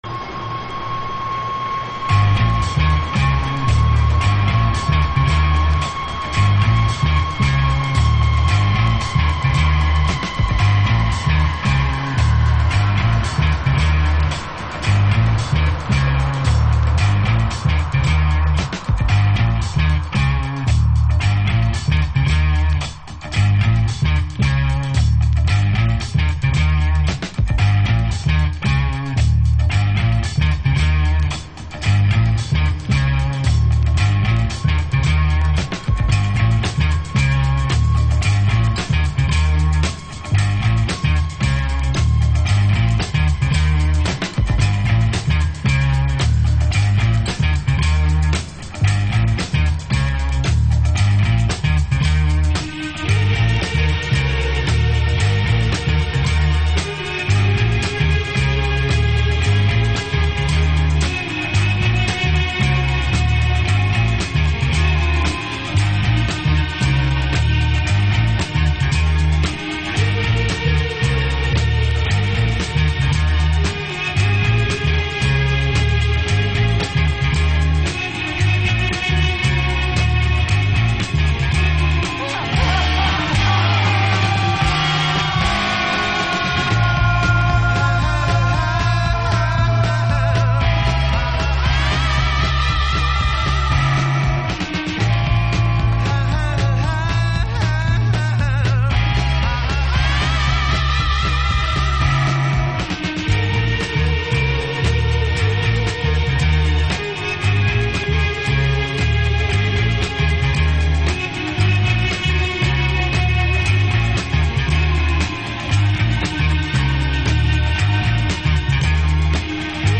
60'sサイケや昨今のサイケデリック音響のレイドバックビートをお探しの方にも聴いていただきたいサウンド。